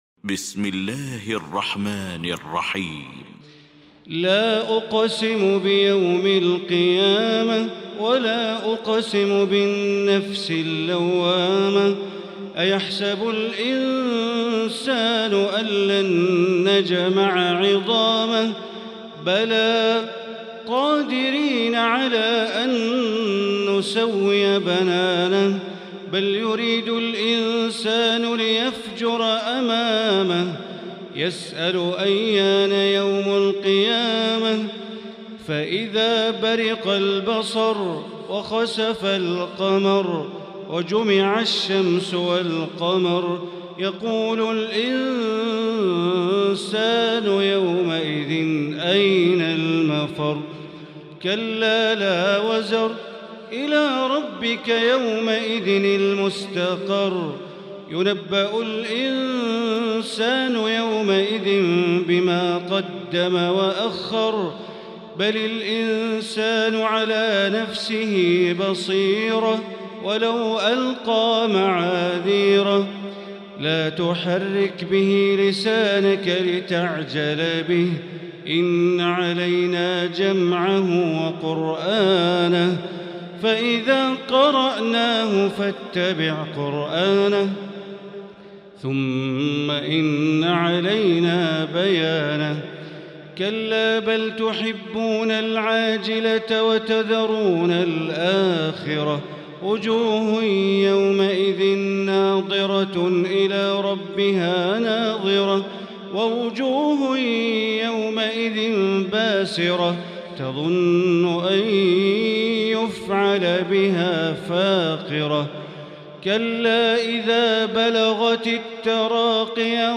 المكان: المسجد الحرام الشيخ: معالي الشيخ أ.د. بندر بليلة معالي الشيخ أ.د. بندر بليلة القيامة